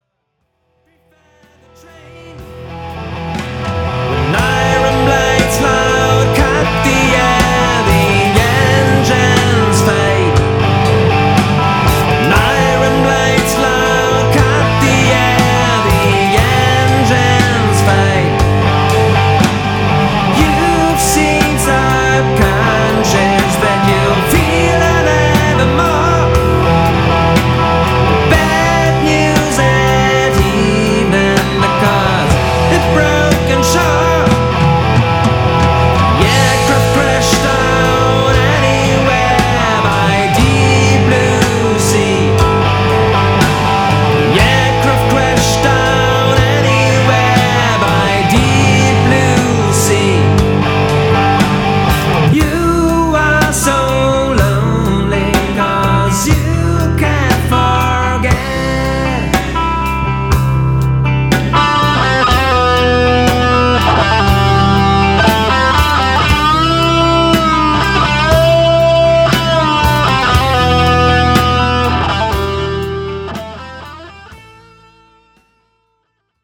Aircraft Accident_Cut.mp3